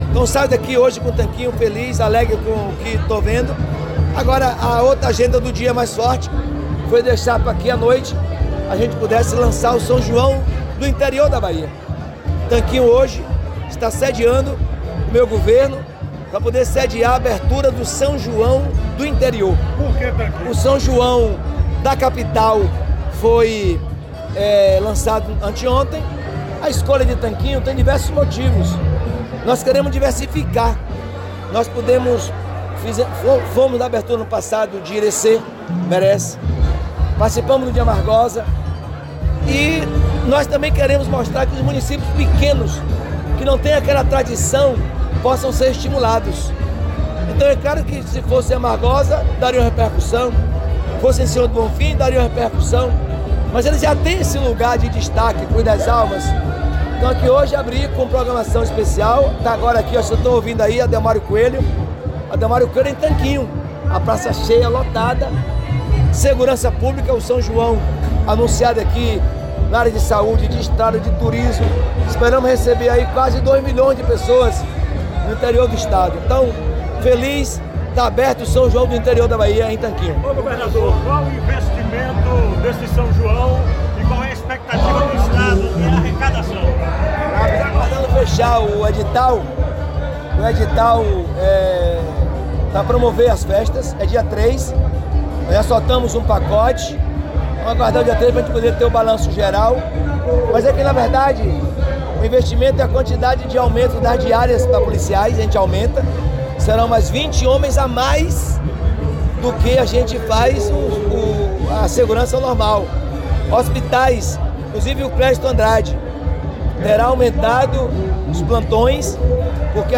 🎙Jerônimo Rodrigues, governador da Bahia